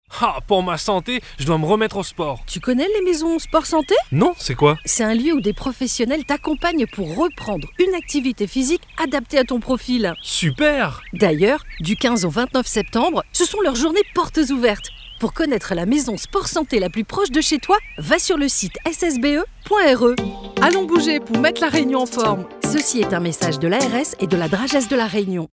• une campagne radio :